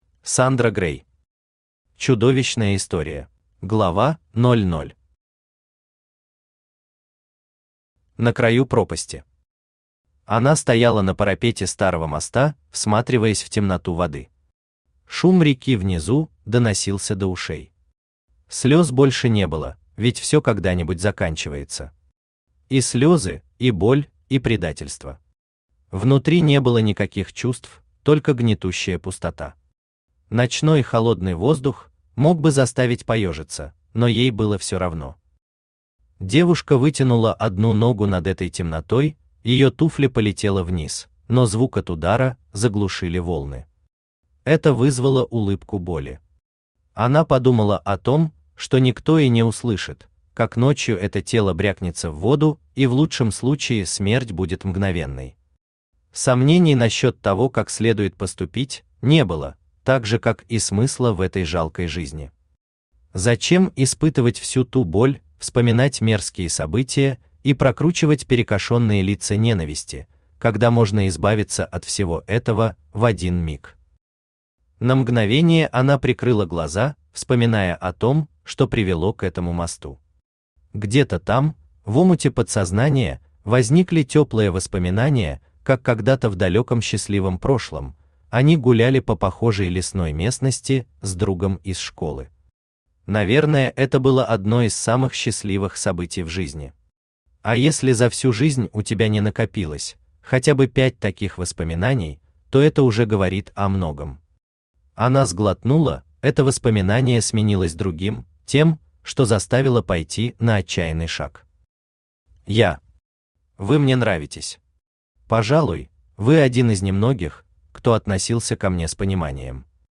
Аудиокнига Чудовищная история | Библиотека аудиокниг
Aудиокнига Чудовищная история Автор Сандра Грей Читает аудиокнигу Авточтец ЛитРес.